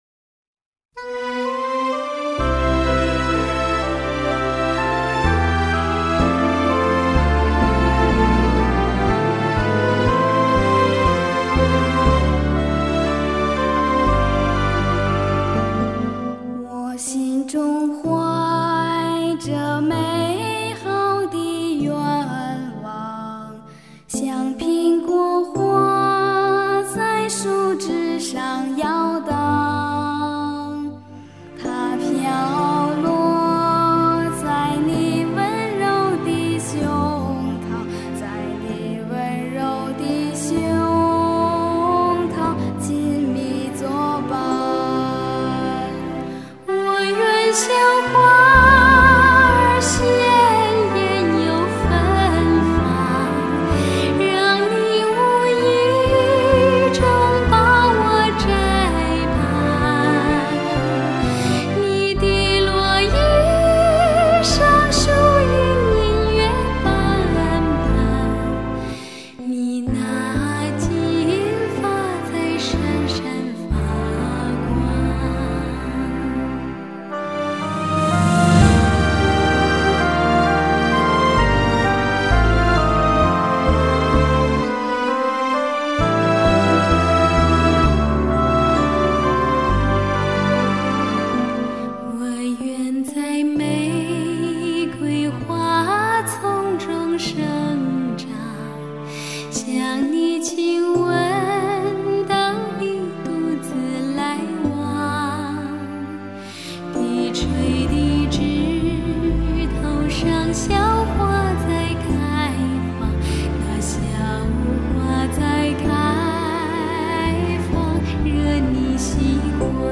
简单而直白的歌声呈现了淡淡哀愁的清幽。
整张唱片融合了女声的甜美飘逸和童声的纯雅清亮，特有的组合理所当然的唤起听者别样的情怀，这是唱片的最大特色，
中国跨界女声与清纯童声联袂组合
英国民谣